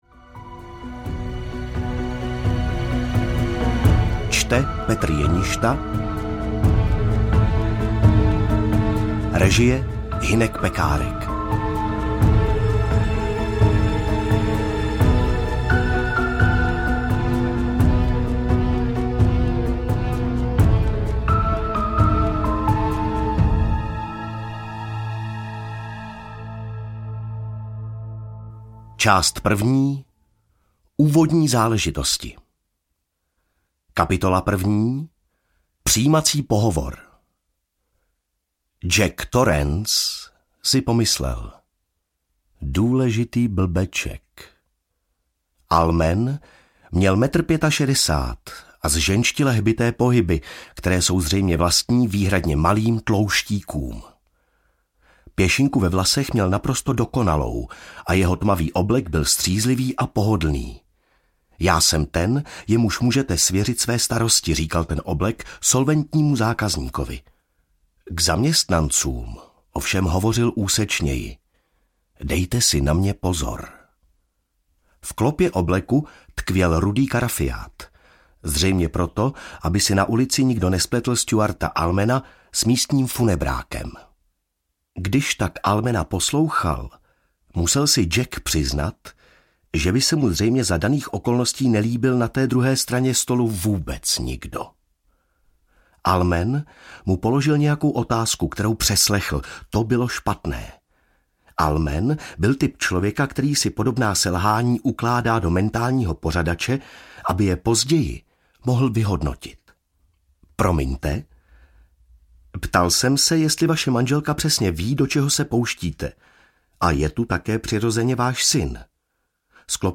Osvícení audiokniha
Ukázka z knihy
osviceni-audiokniha